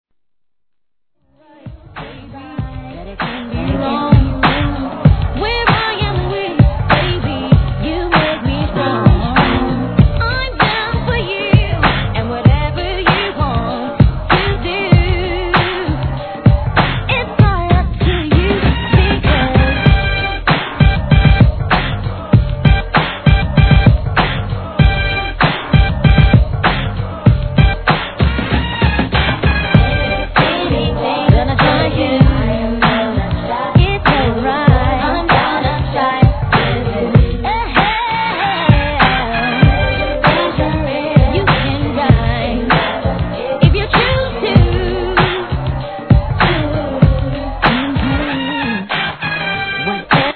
C / 盤面キズ多いです
HIP HOP/R&B
(96 BPM)